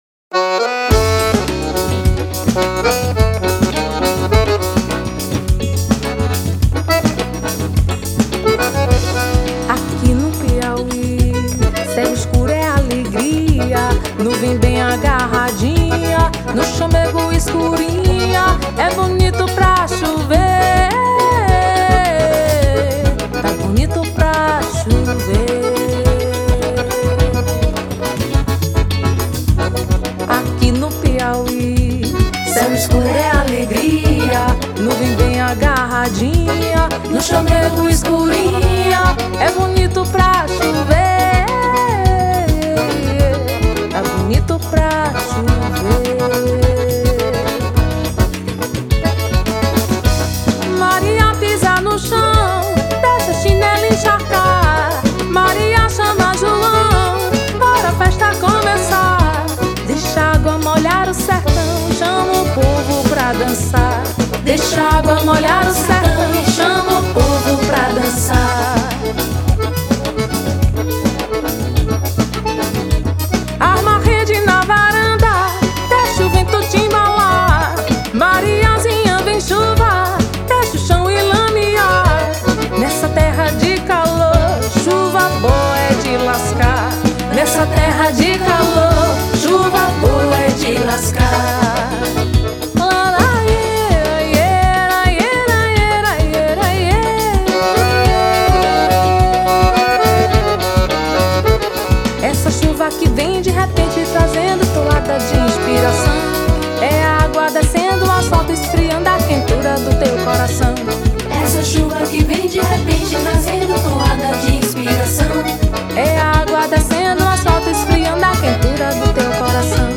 05:20:00   Baião
Triângulo, Vocal, Voz
Acoordeon, Vocal
Zabumba, Voz
Baixo Elétrico 6, Bateria, Triângulo